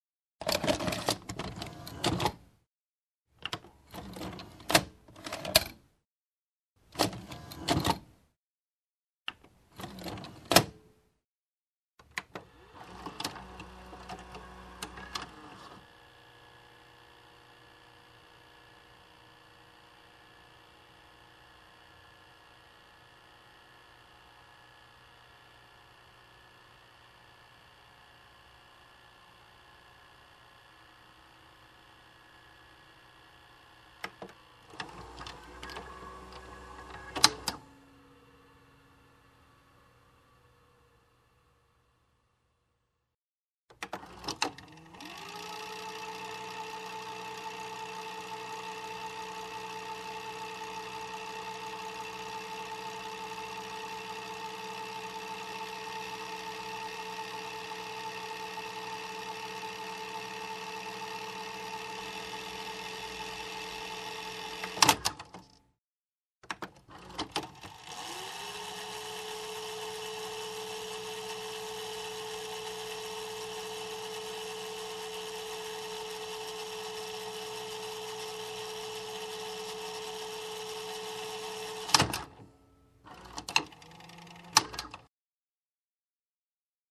Шум воспроизведения VHS кассеты